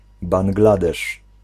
Ääntäminen
Ääntäminen : IPA : /ˌbæŋ.ɡlə.ˈdɛʃ/ US : IPA : [ˌbæŋ.ɡlə.ˈdɛʃ] Lyhenteet ja supistumat (laki) Bangl.